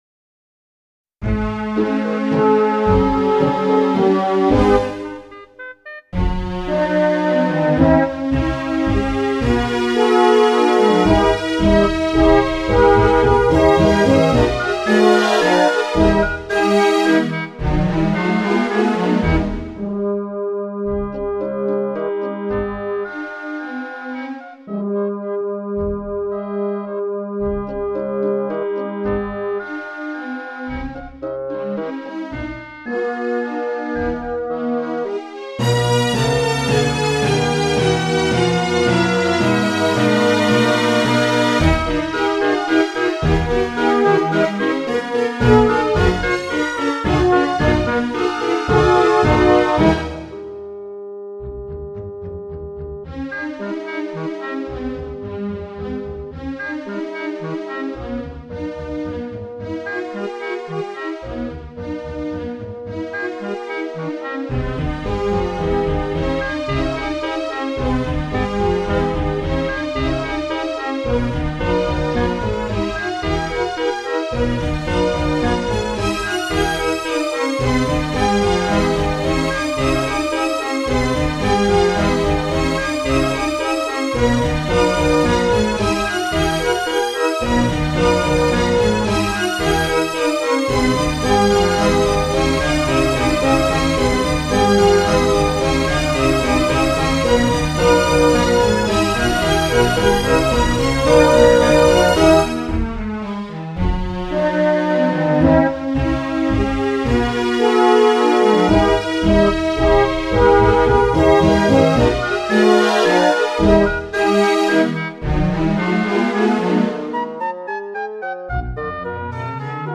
This is my attempt at writing for an orchestra.